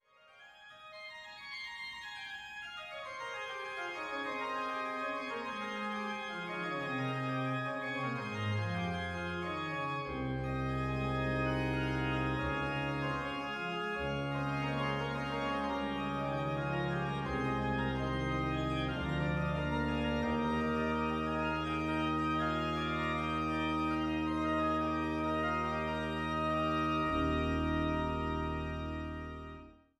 Lebusa